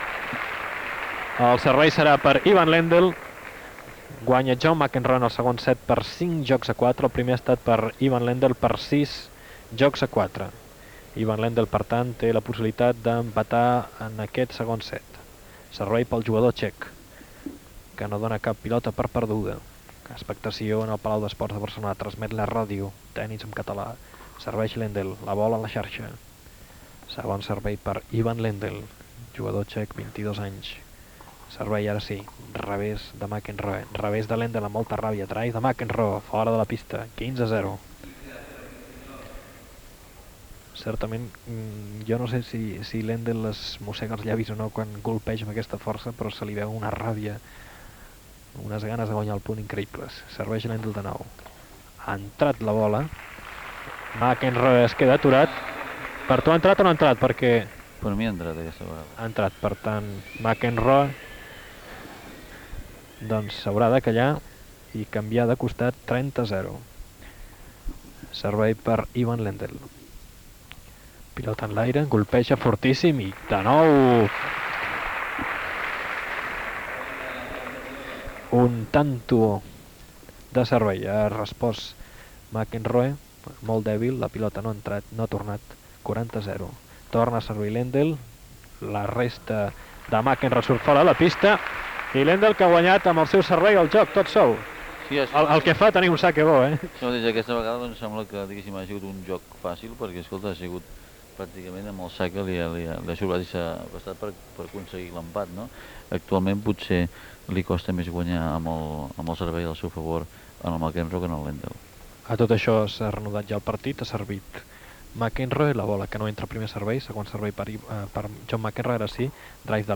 Narració i comentaris de diverses jugades del segon set del partit de tennis entre Ivan Lendel i McEnroe en el torneig d'exhibició "Europa versus América" disputat al Palau d'Esports de Barcelona